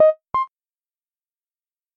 Звуки авторизации